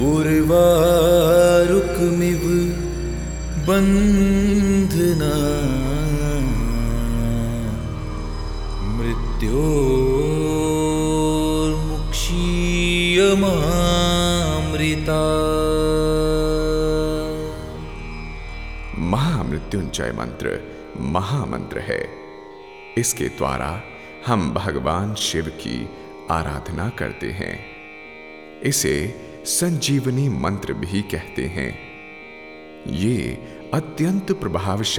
# Devotional & Spiritual